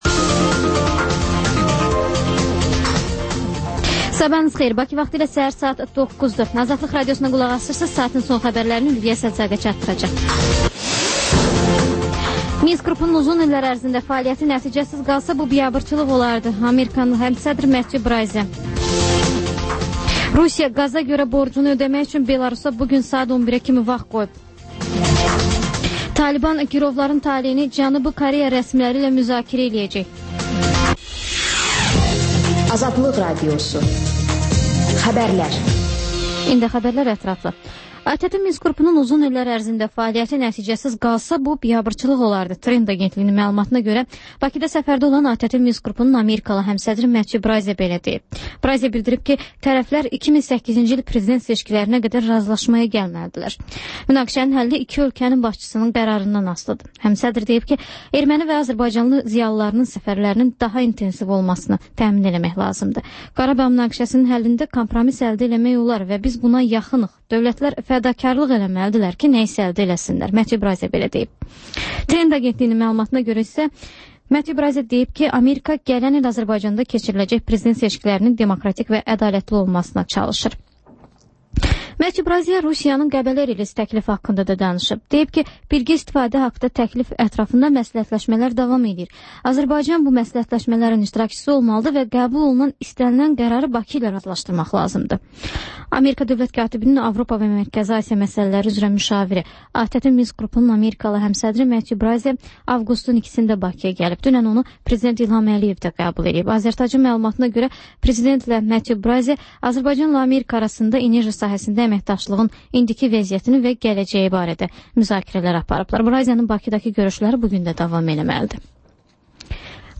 Xəbər-ətər: xəbərlər, müsahibələr, sonra 14-24: Gənclər üçün xüsusi veriliş